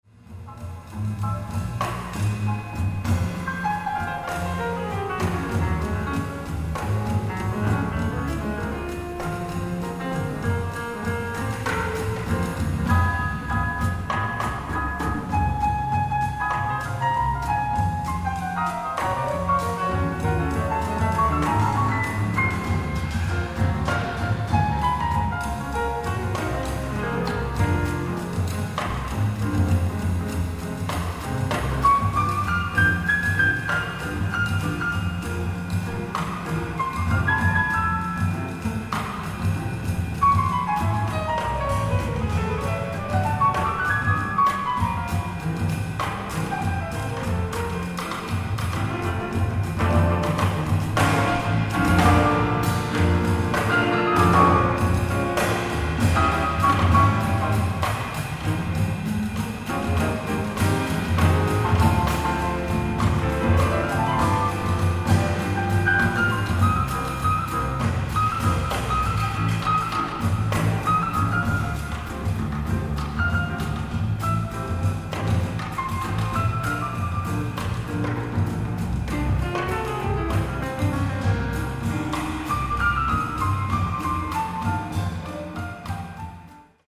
ライブ・アット・サントリー・ホール、東京 05/31/2019
※試聴用に実際より音質を落としています。